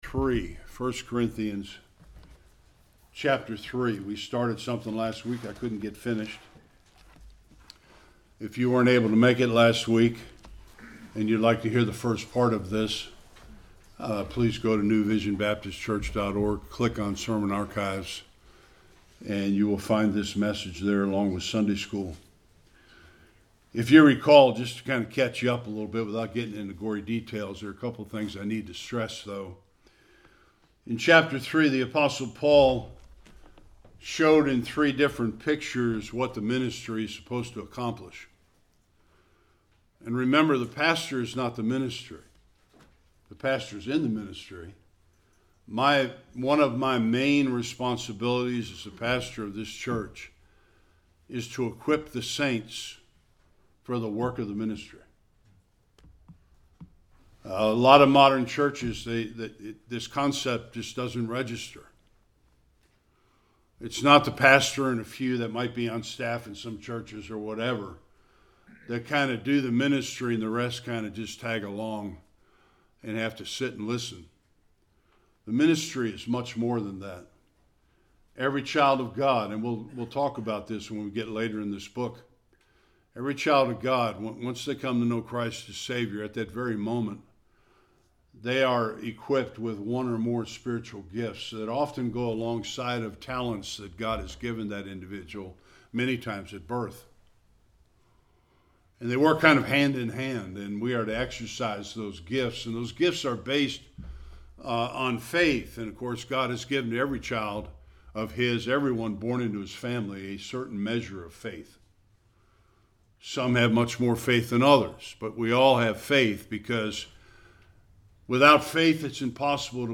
5-23 Service Type: Sunday Worship The church is losing its way.